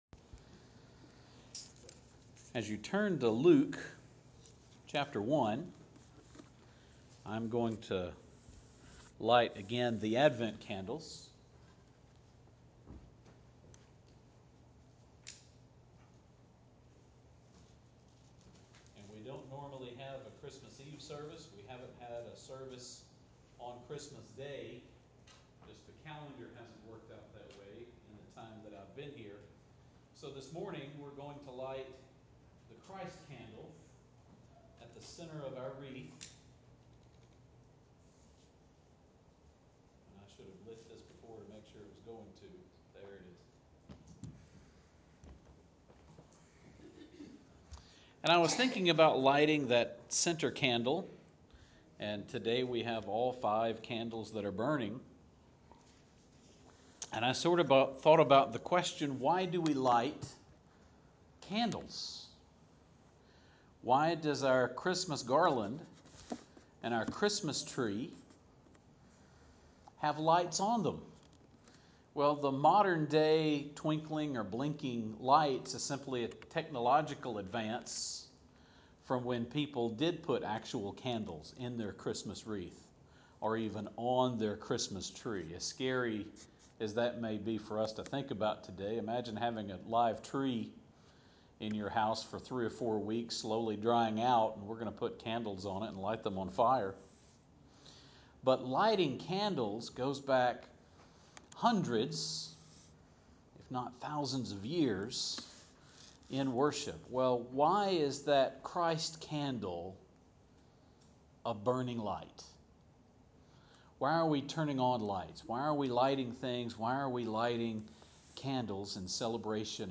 On Christmas Day I had the rare opportunity to light the center Christ candle in our Advent wreath.